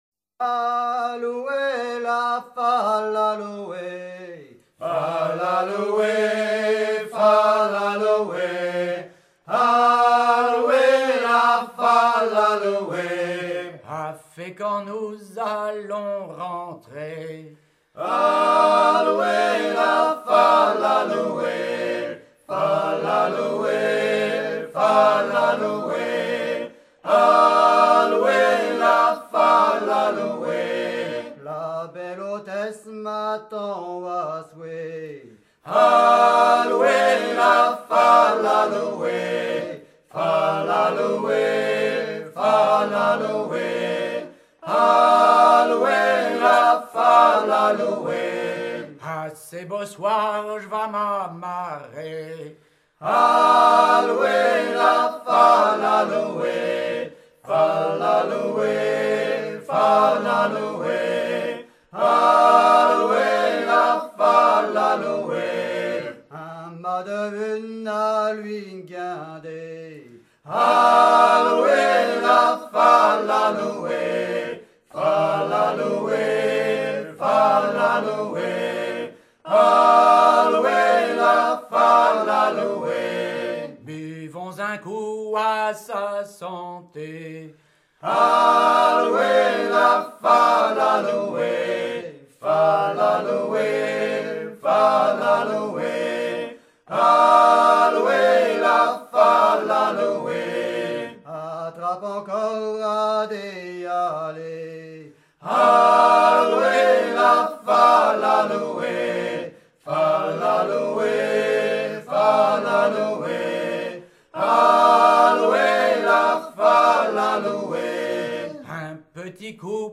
chants brefs
(les paroles sont improvisées)
à haler
maritimes
Pièce musicale éditée